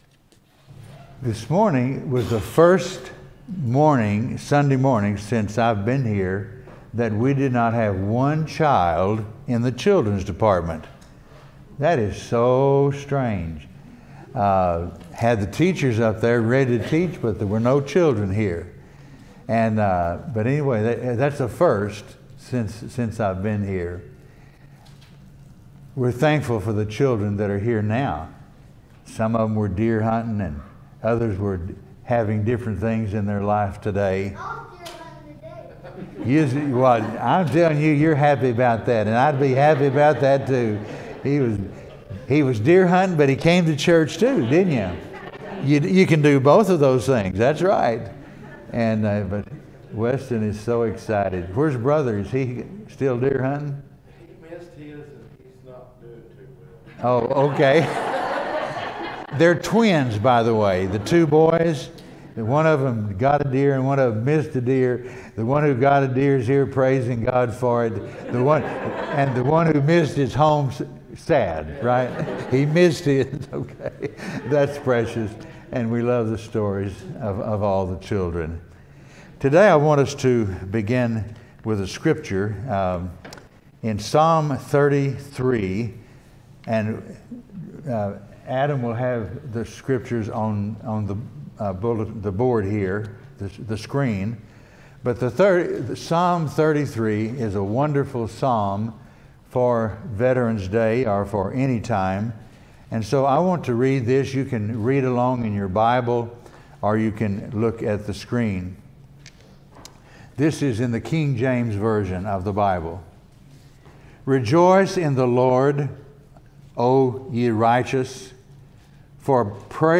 Veteran's Day 2020 service.
Psalm 33 Service Type: Sunday Morning Veteran's Day 2020 service.